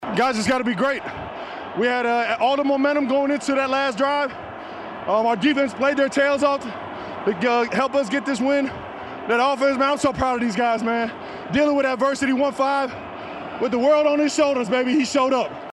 Kelce talked with the NFLNetwork after the game.